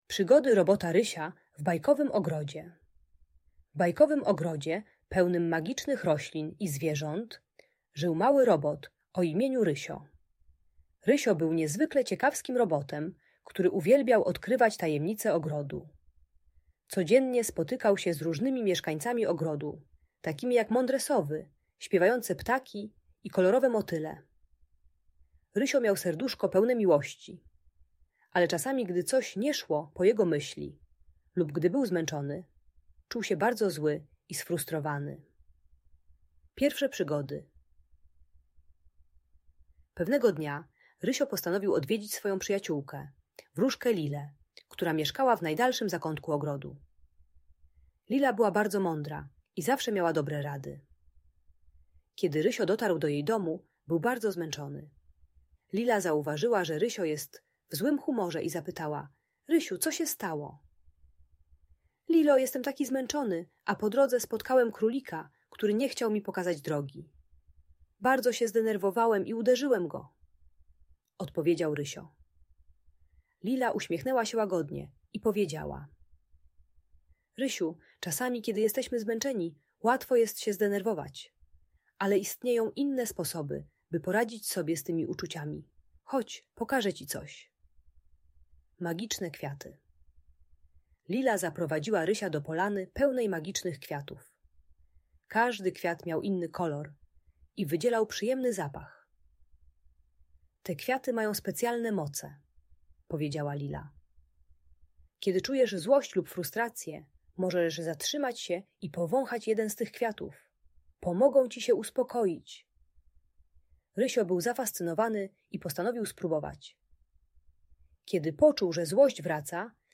Przygody Robota Rysia: Magiczna Bajkowa Historia - Audiobajka